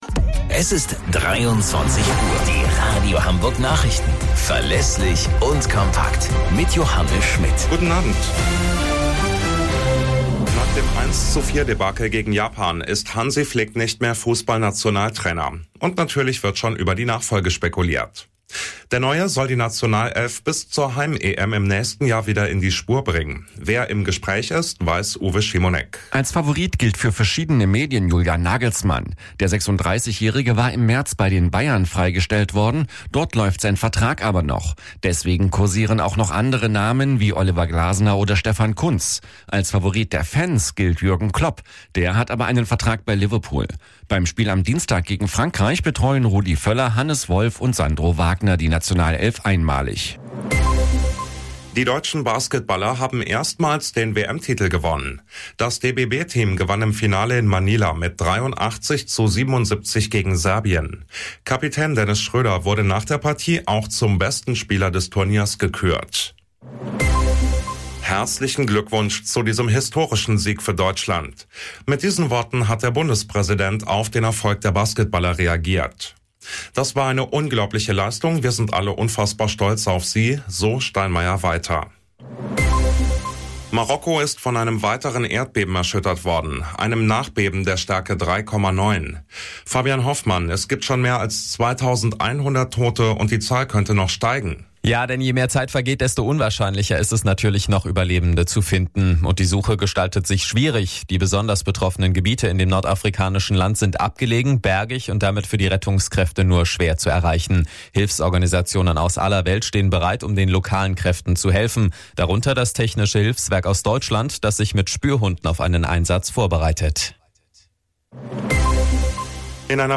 Radio Hamburg Nachrichten vom 11.09.2023 um 00 Uhr - 11.09.2023